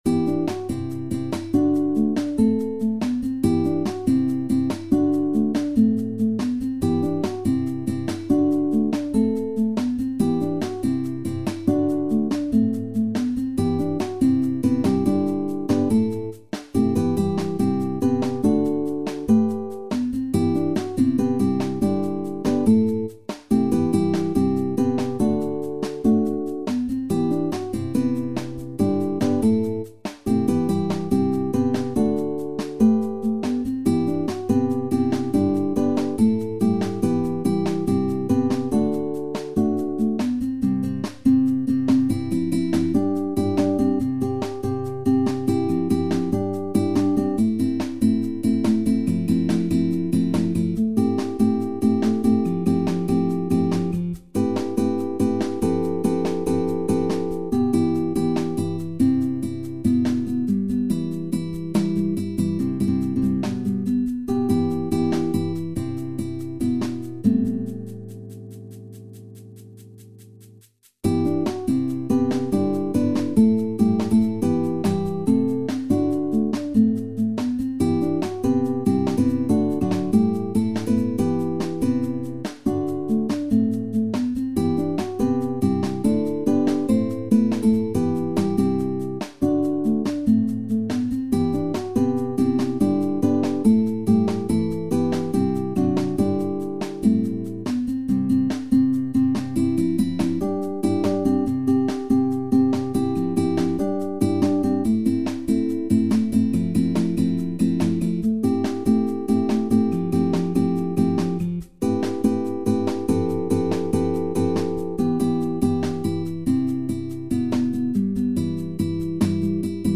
SSATB | SATTB
in een ruig arrangement